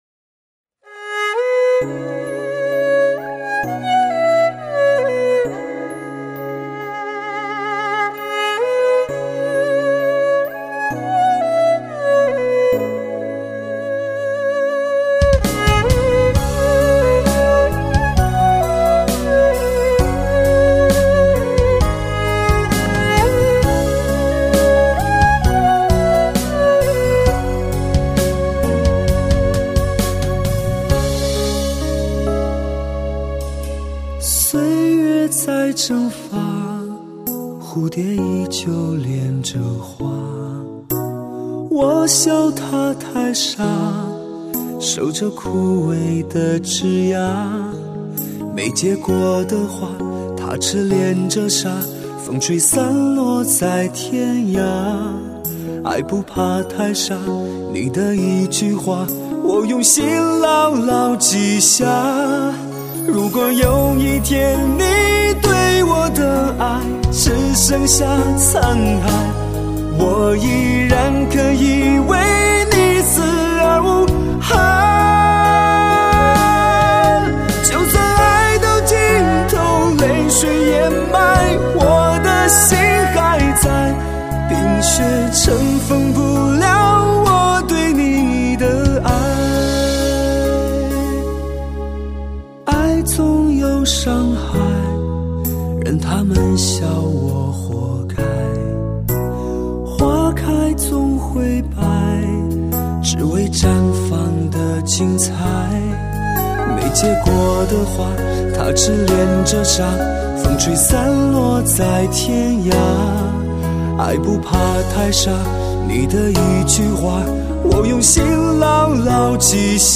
类型: 天籁人声
他的音质天生多情，倔强中透露着柔软，和着音乐似无意、亦有意的碰触让人生疼……